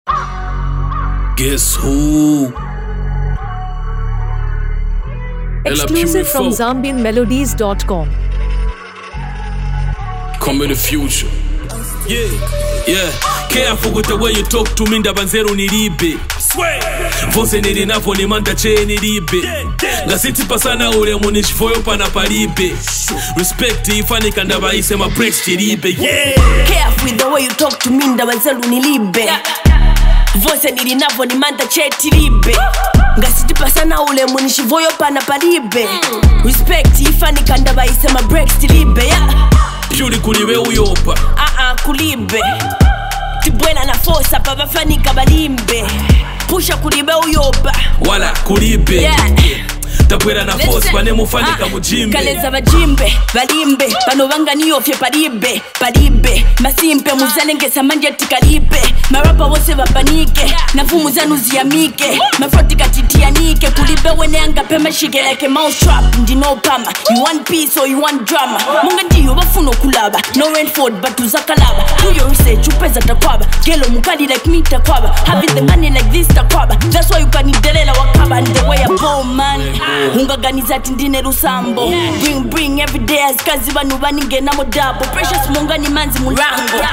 Zambian Afrobeat